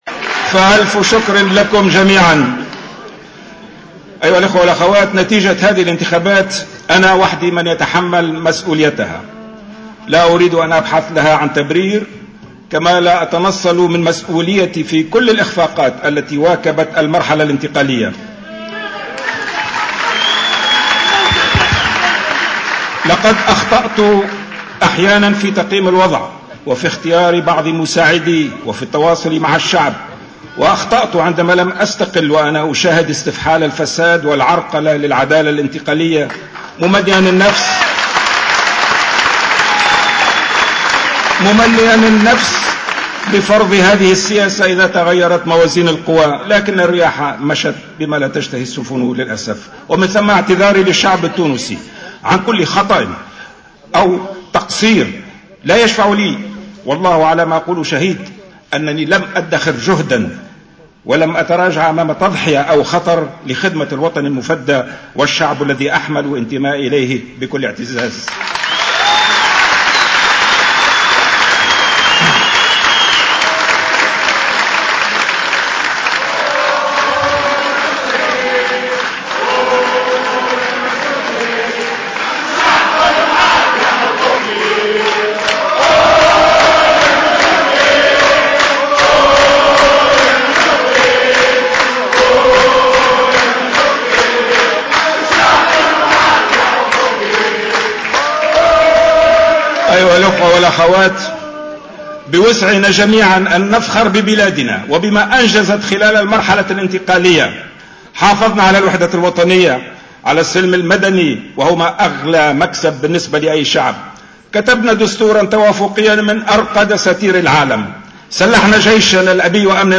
Ecoutez le discours de Marzouki : Moncef Marzouki t√©l√©charger partager sur